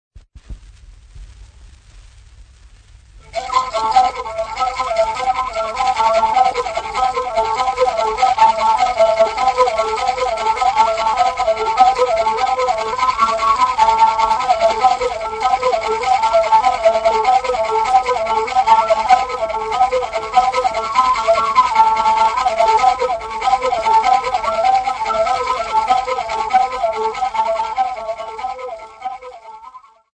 Folk Music
Field recordings
Africa Tanzania city not specified f-tz
sound recording-musical
Indigenous music